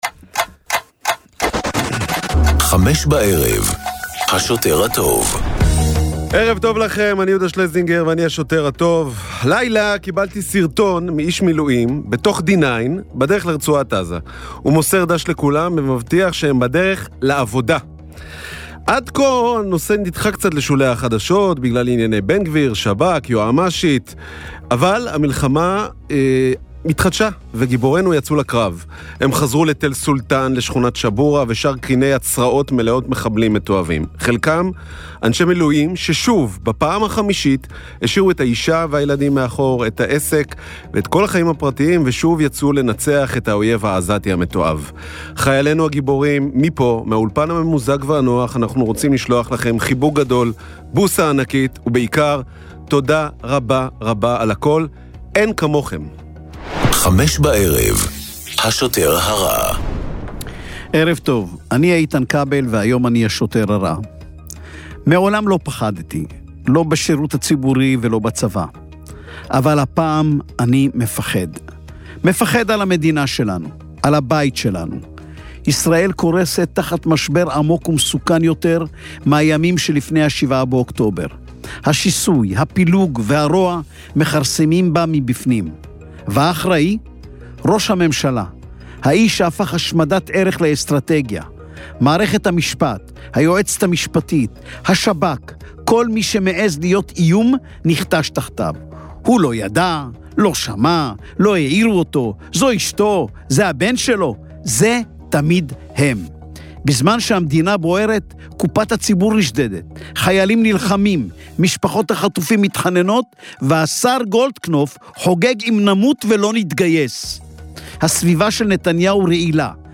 בן כספית, מהעיתונאים הבכירים והבולטים כיום במדינת ישראל, ופרופסור אריה אלדד, רופא, פובליציסט וכמובן חבר כנסת לשעבר מטעם האיחוד הלאומי ועוצמה לישראל, מגישים יחד תכנית אקטואליה חריפה וחדה המורכבת מריאיונות עם אישים בולטים והתעסקות בנושאים הבוערים שעל סדר היום. השילוב הנפיץ של השניים מייחד את התכנית ומוביל כל דיון לכיוונים לא צפויים.